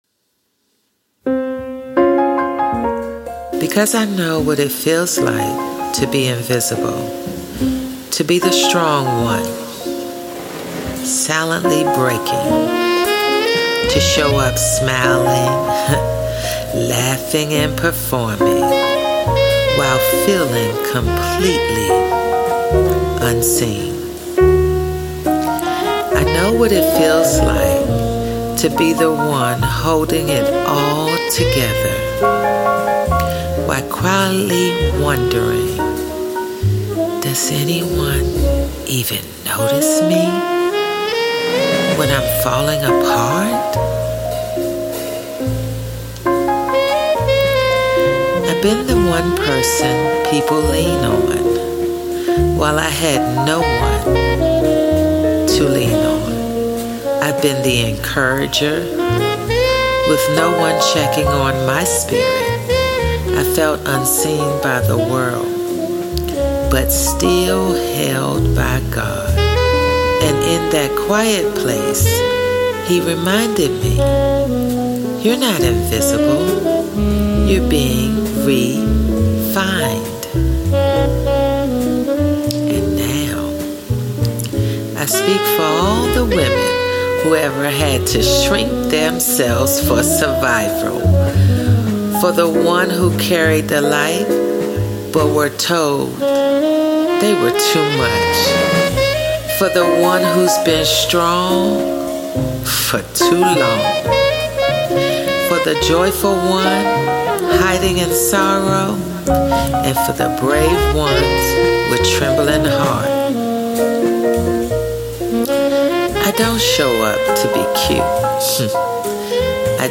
Play Rate Listened List Bookmark Get this podcast via API From The Podcast A powerful spoken-soul anthem rooted in the truth of who I am.